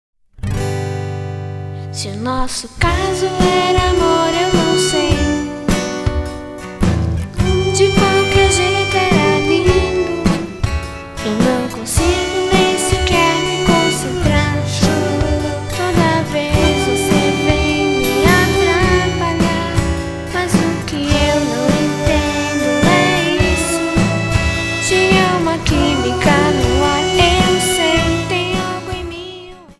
Violão, baixo, escaleta e bateria